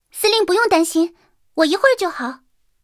三号小破修理语音.OGG